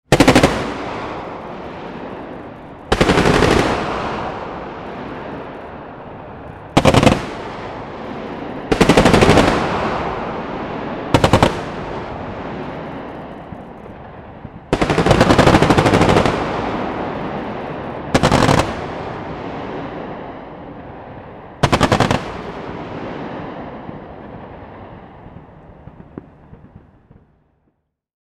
Intense Anti-Aircraft Gunfire Barrage Sound Effect
Description: Intense anti-aircraft gunfire barrage sound effect.
Genres: Sound Effects
Intense-anti-aircraft-gunfire-barrage-sound-effect.mp3